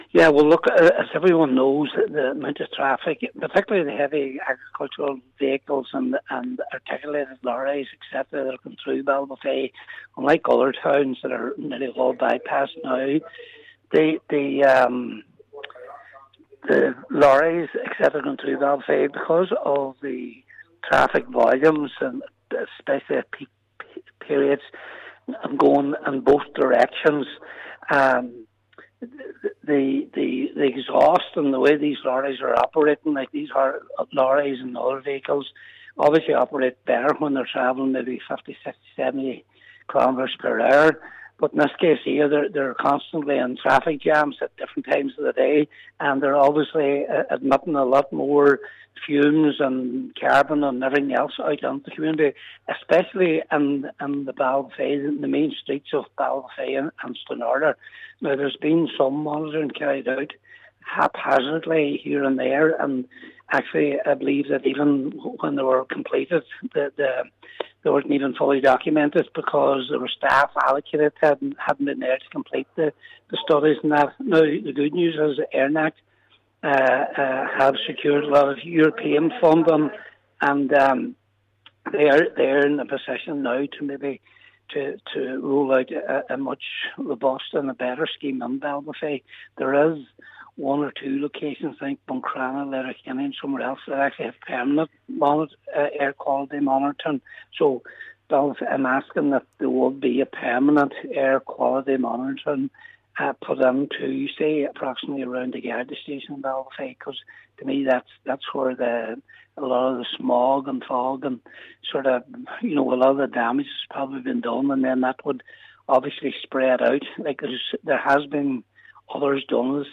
(Full interview)